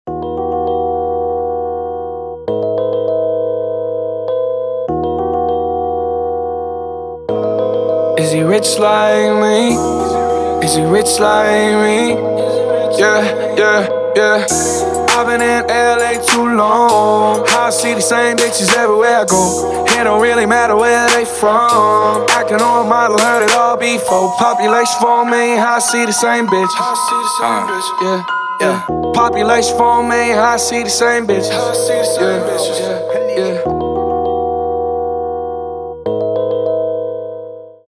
• Качество: 320, Stereo
Хип-хоп
мелодичные
колокольчики
Piano